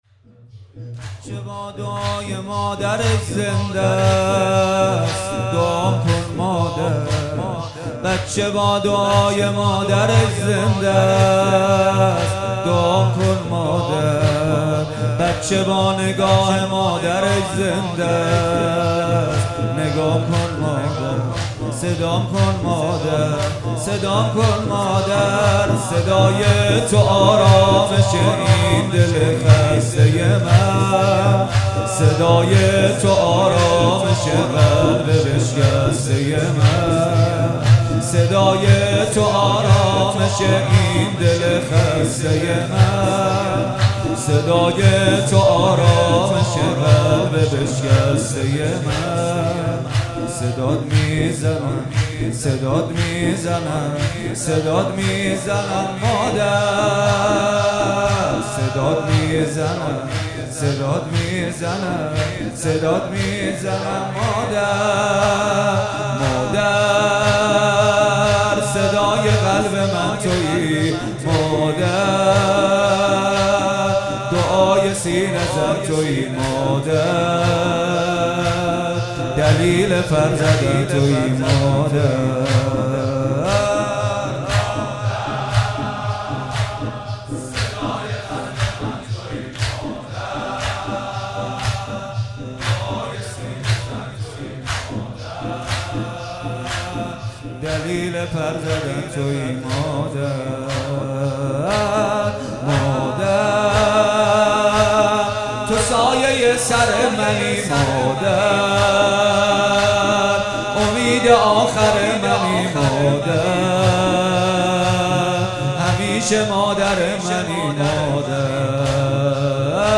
فاطمیه دوم|مداحی
در شب اول فاطمیه دوم 96 هیئت ریحانه النبی (س)